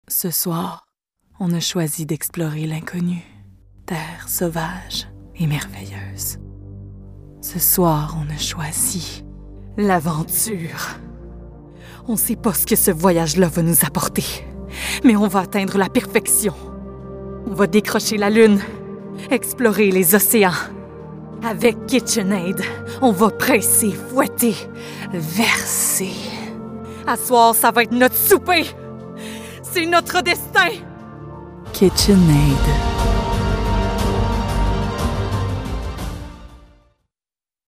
Voix déterminée_Kitchen Aid
Voix-déterminée_Kitchen-Aid.mp3